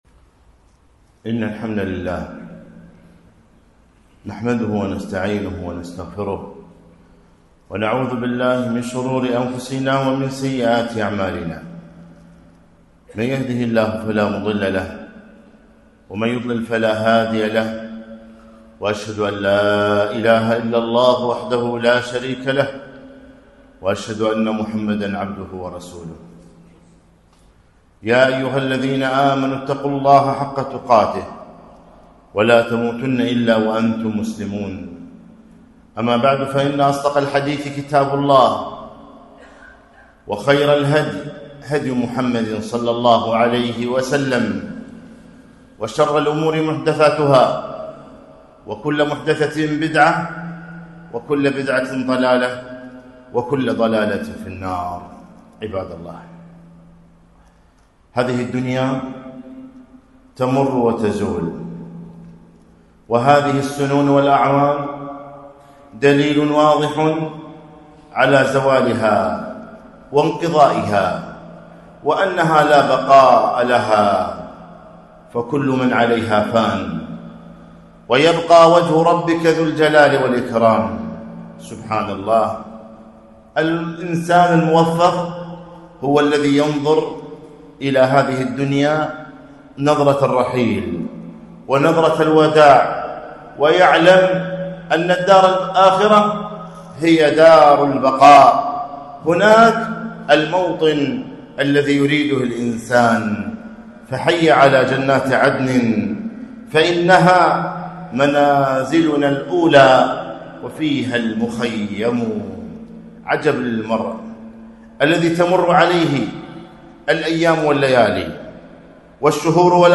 خطبة - إنما أنت أيام